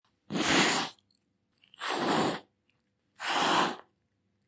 Very Angry Cat Téléchargement d'Effet Sonore
Very Angry Cat Bouton sonore